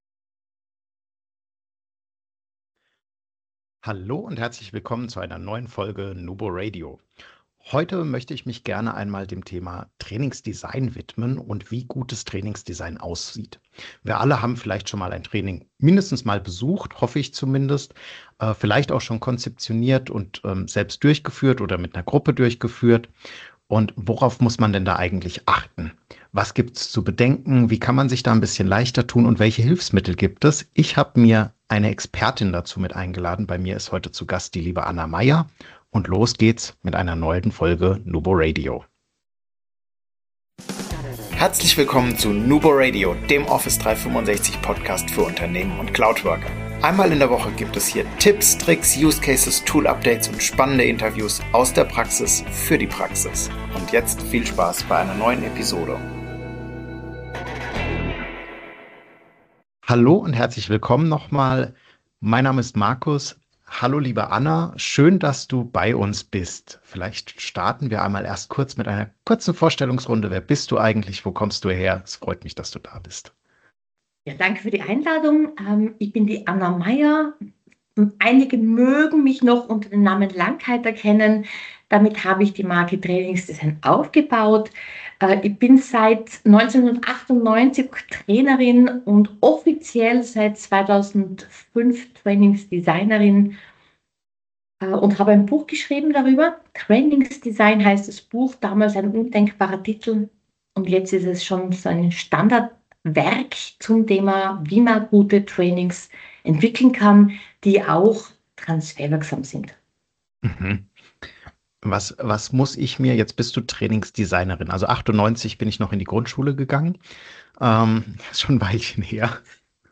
Interviewepisode